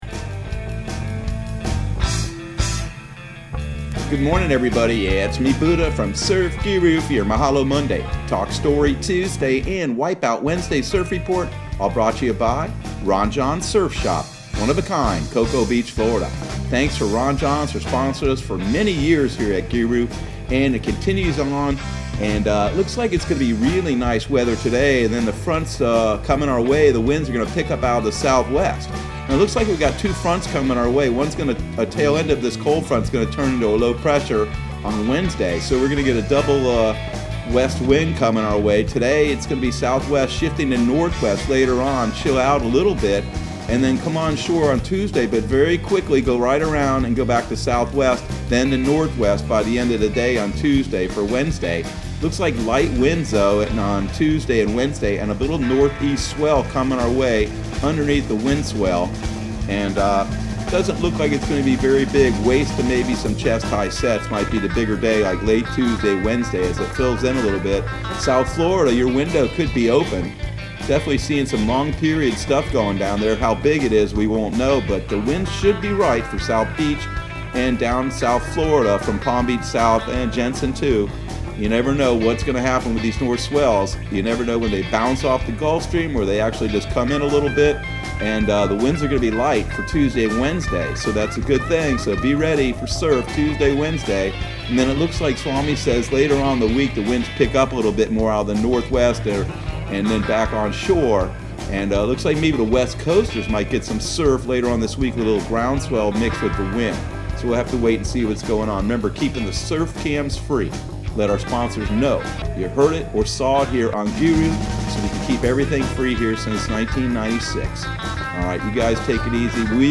Surf Guru Surf Report and Forecast 12/14/2020 Audio surf report and surf forecast on December 14 for Central Florida and the Southeast.